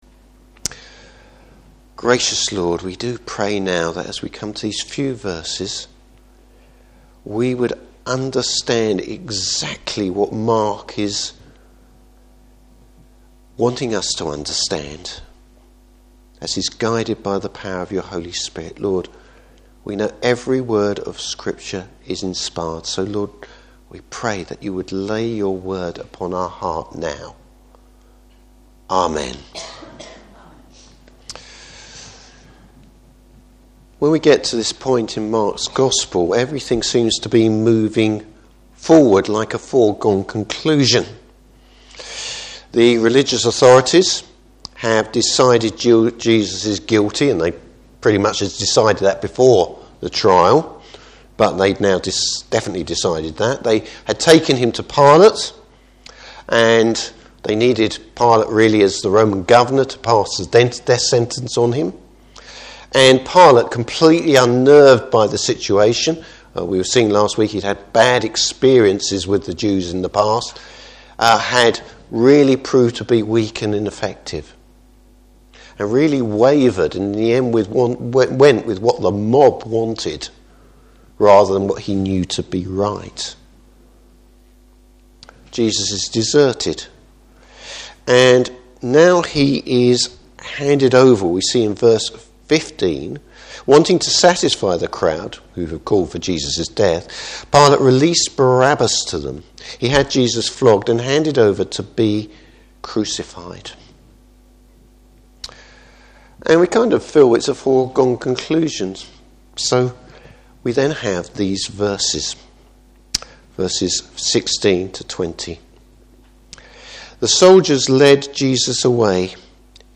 Service Type: Morning Service Who’s responsible for Jesus’ death?